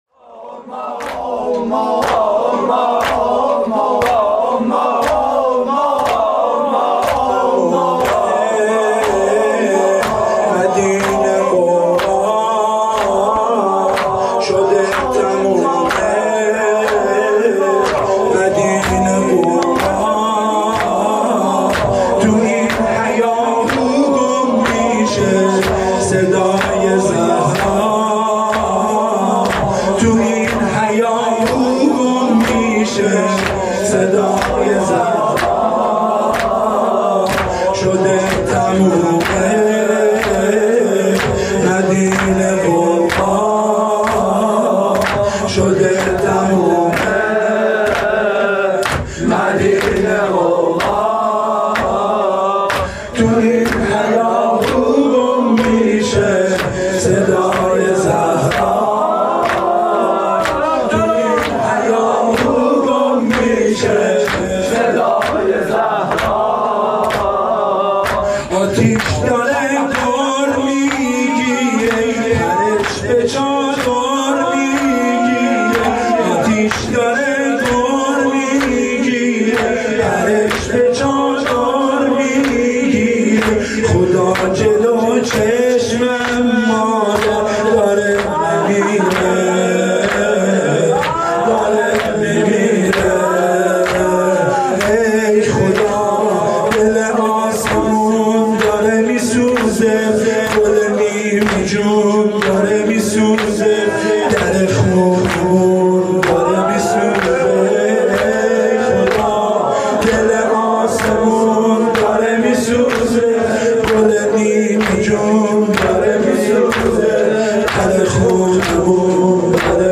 حسینیه, فاطمیه